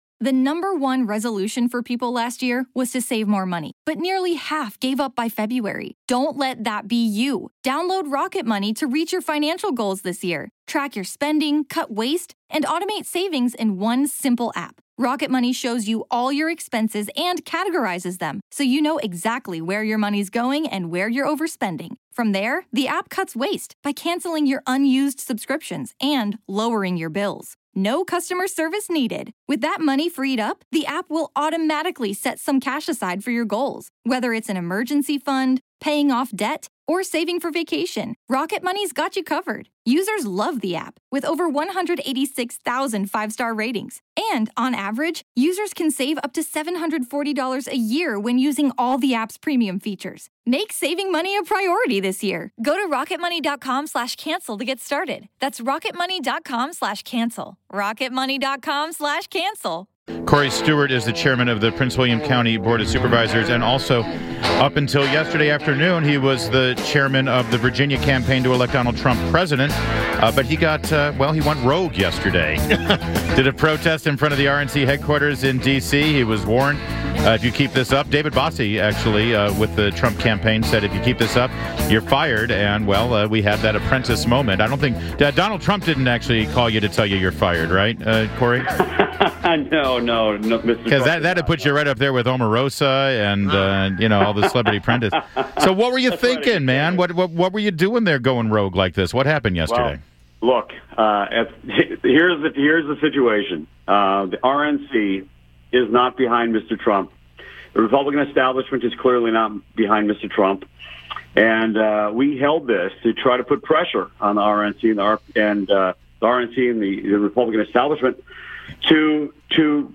INTERVIEW — COREY STEWART – is the chair of the Prince William County Board of Supervisors and former Virginia campaign chair for Donald Trump